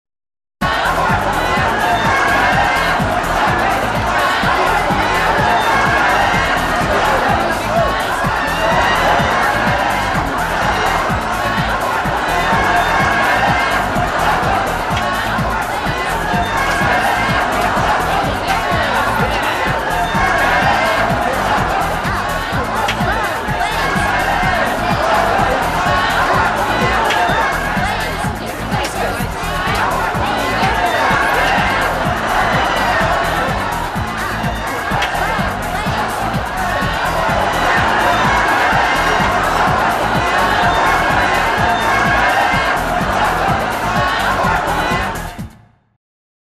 На этой странице собраны яркие и радостные звуки детского праздника: смех, веселые мелодии, фанфары и другие аудиоэффекты.
Праздничная атмосфера с яркими звуками